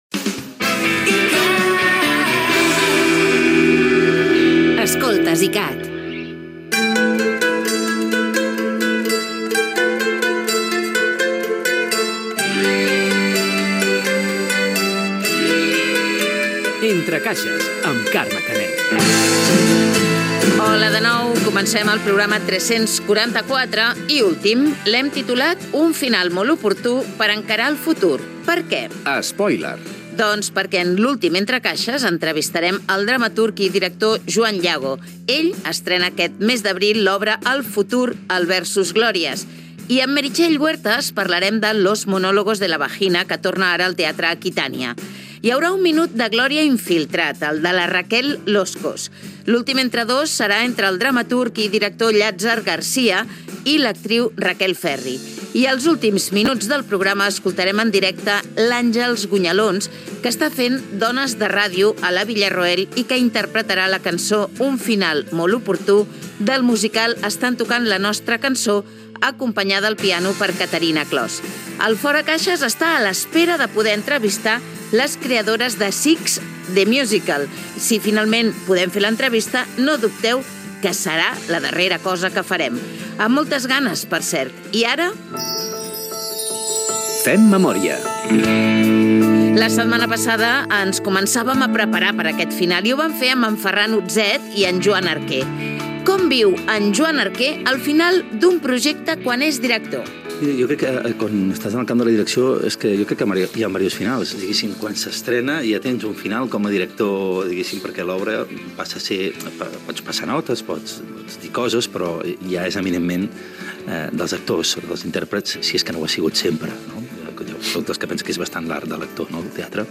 Indicatiu de la ràdio, presentació de l'última edició del programa amb el sumari de continguts
Tema musical.
Gènere radiofònic Cultura